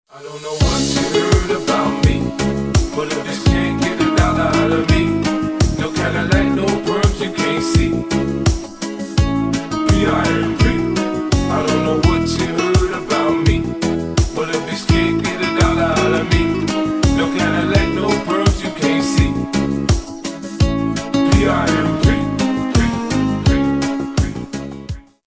спокойные , mashup
рэп , rnb